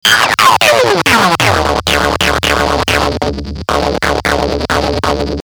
Soundfiles from the modular
(mono, 256KPS, 44,1KHz)
hewavydistfilter2.mp3